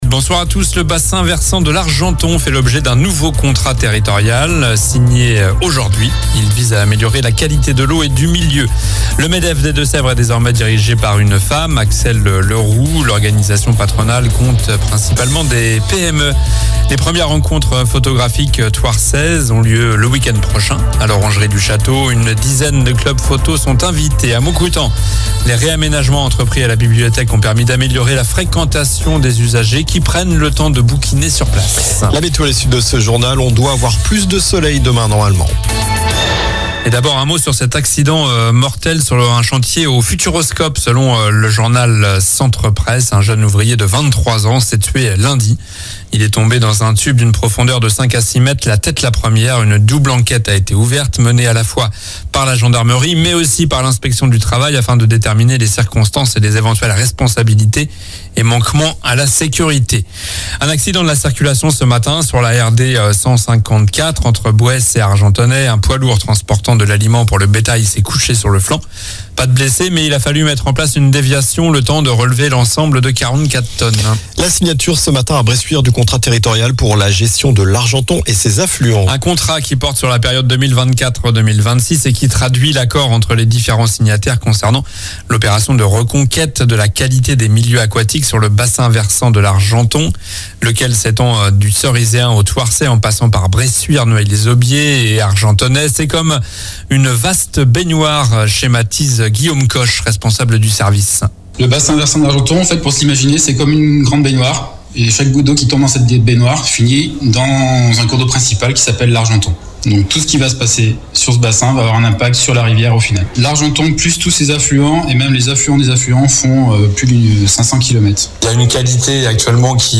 Journal du mercredi 17 avril (soir)